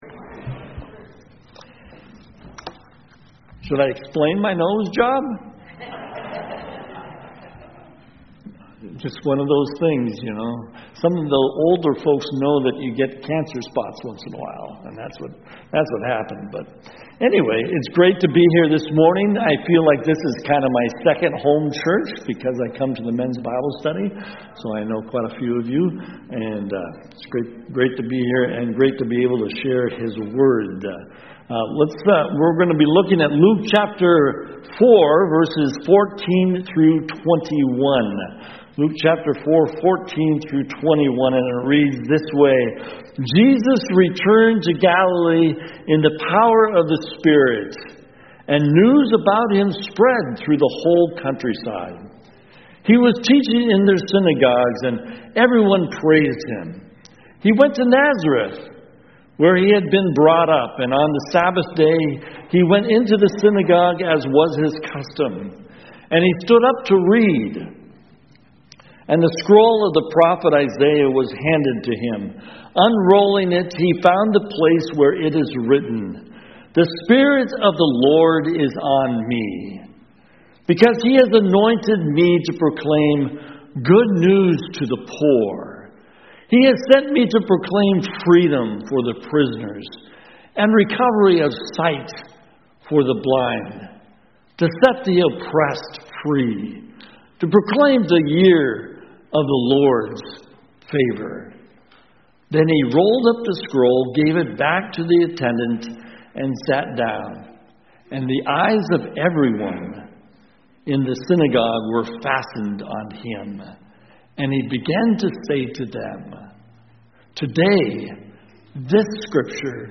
CoJ Sermons Good News For…